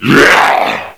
assets/nx/nzportable/nzp/sounds/zombie/a7.wav at 9a1952eb13f6e072cf1865de2f705dbe1bf677f1